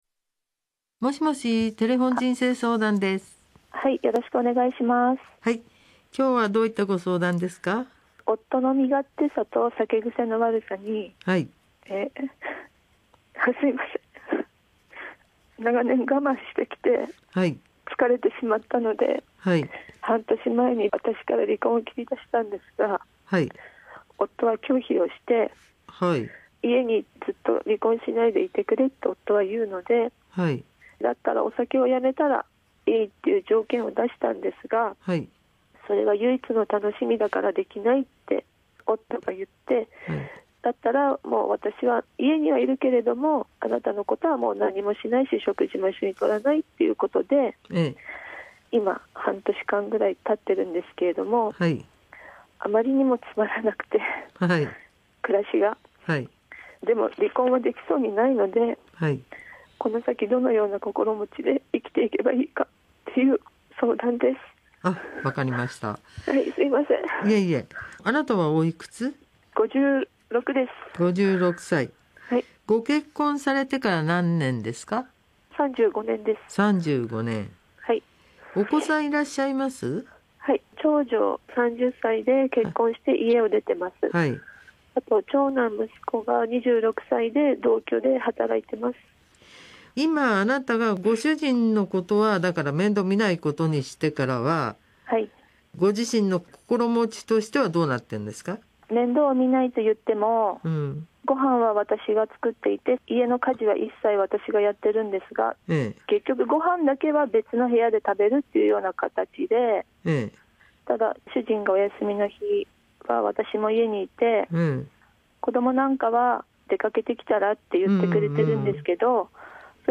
夫婦の悩み相談 番組もお手上げ。